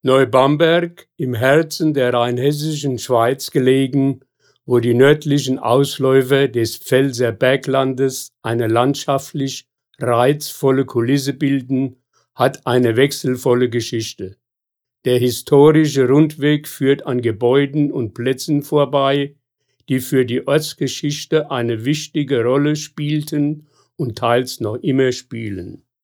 Hier geht es zur Ersten Station Hier können Sie die Hörfassung des Historischen Rundwegs abspielen!
00 Einleitung Historischer Rundgang Neu-Bamberg.wav